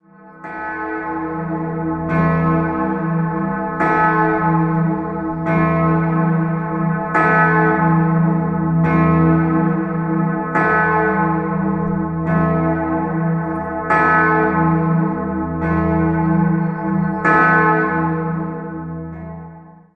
Schützenglocke
Die Schützenglocke im Nordturm ist die viertgrößte Glocke Österreichs, wiegt ca. 9.200 kg und wurde 1959 von der Gießerei Grassmayr in Innsbruck hergestellt.
Erst 1959 konnte durch eine Stiftung wieder eine neue e°-Glocke - die Schützenglocke - angeschafft werden. Charakteristisch für diese Glocke ist der stark ausgeprägte Quart-Nebenschlagton a°.